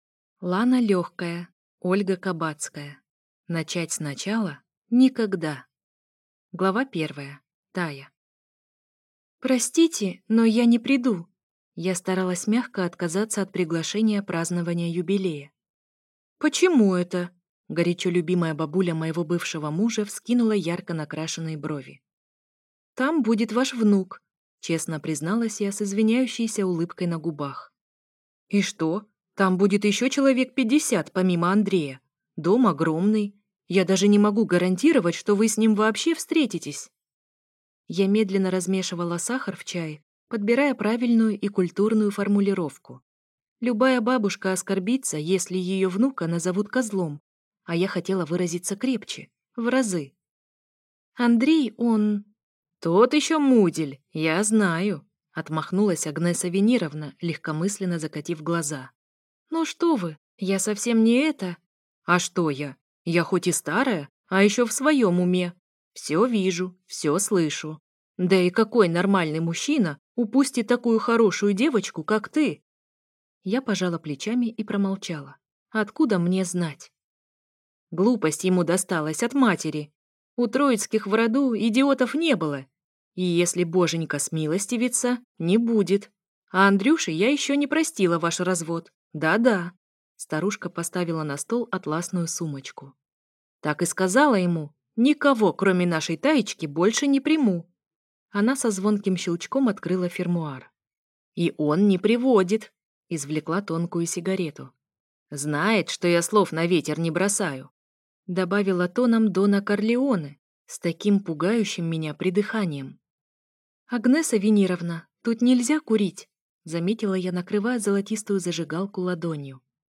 Аудиокнига Начать сначала?! Никогда | Библиотека аудиокниг